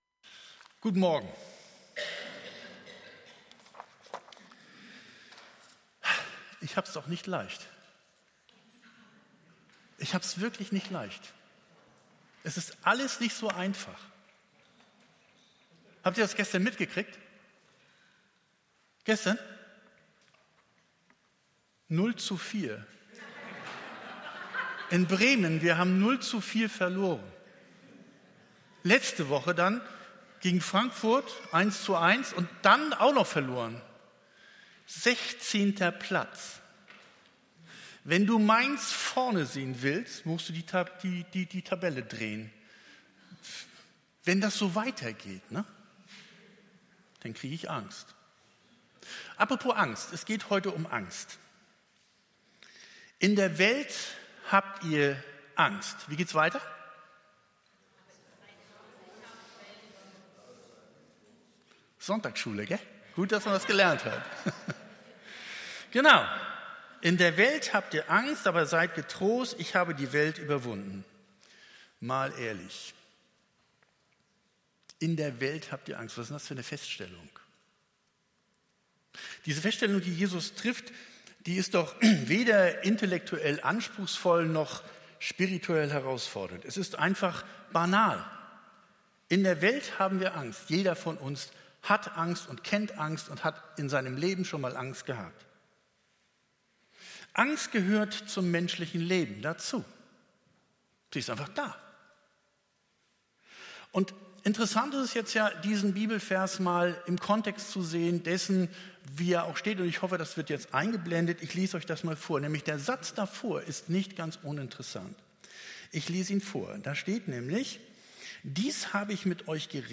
Predigt vom 03.09.2023